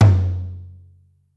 perc 11.wav